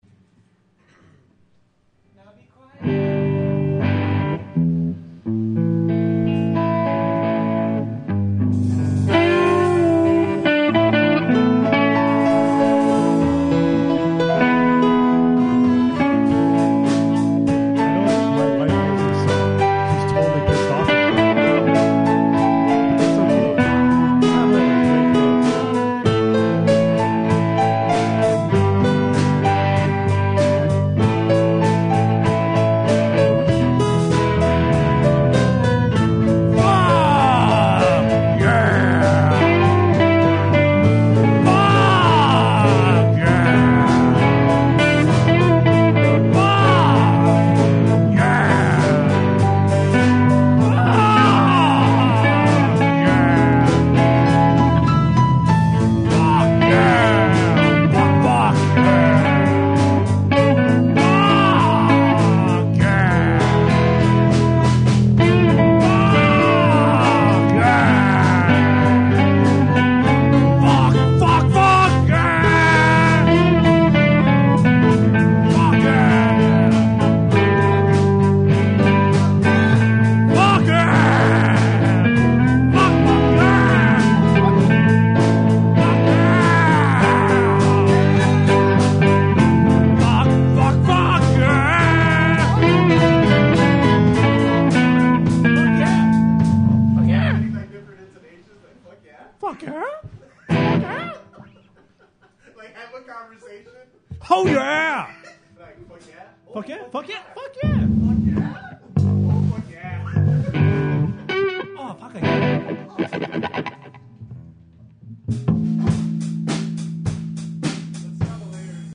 drums
guitar, I'm on bass/viola/piano